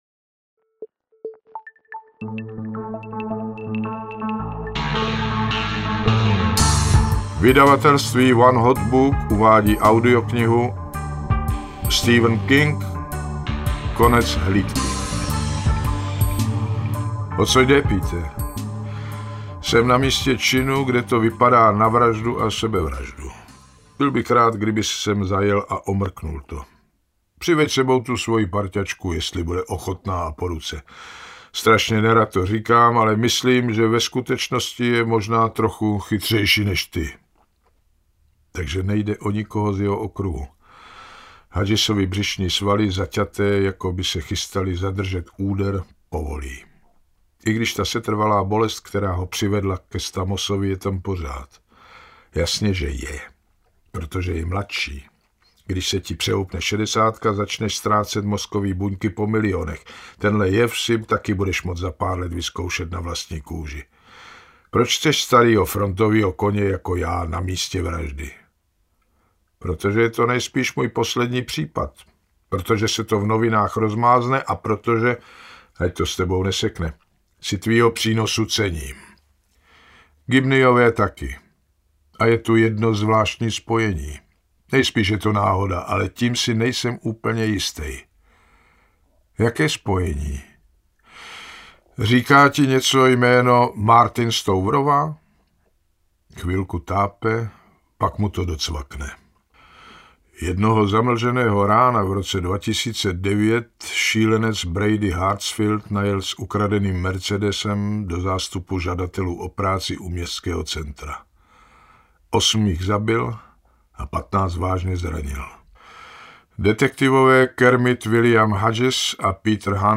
Konec hlídky audiokniha
Ukázka z knihy